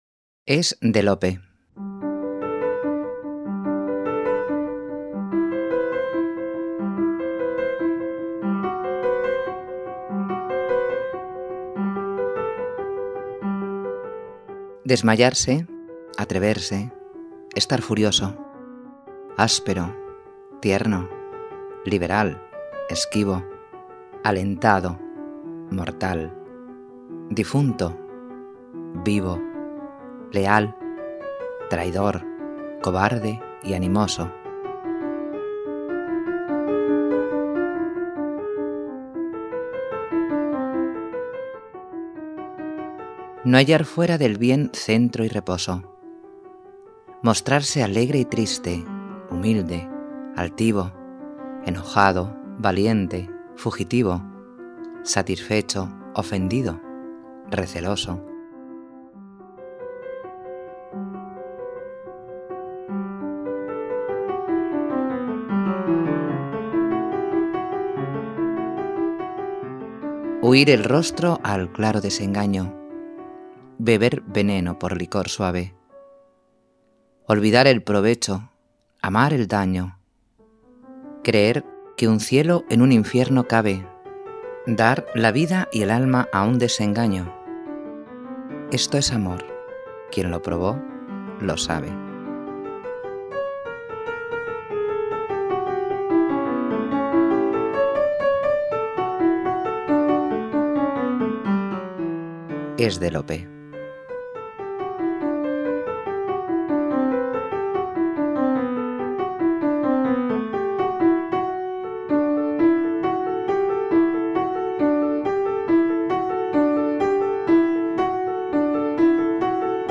Poema
Música: MusOpen
Audiolibro Gratis